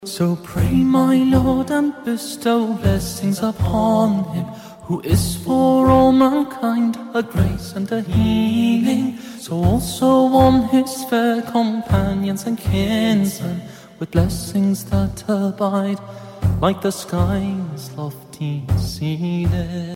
آهنگ با کلام موبایل(ملایم)